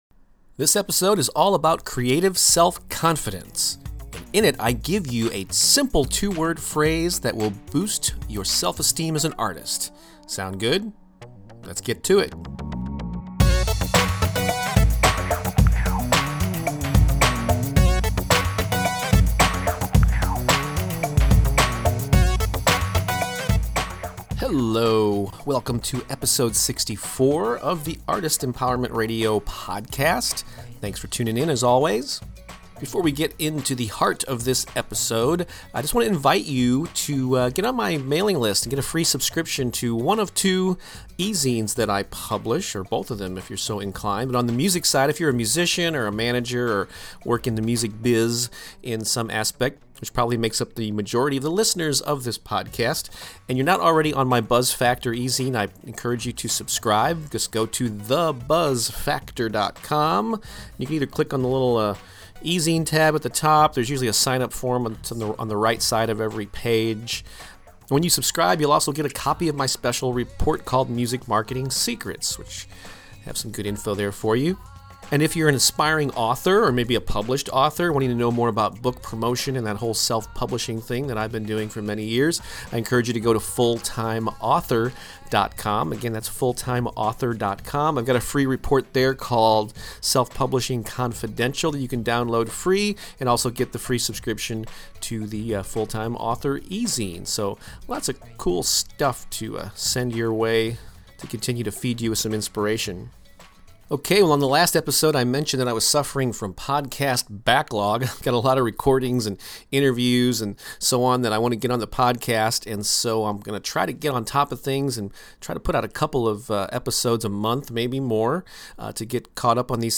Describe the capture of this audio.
this episode was also recorded in an "exotic location,"